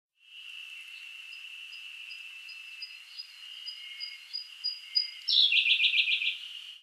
コルリ　Luscunia cyaneツグミ科
奥日光中禅寺湖畔　alt=1270m  HiFi --------------
Mic.: audio-technica AT822
他の自然音：　 エゾハルゼミ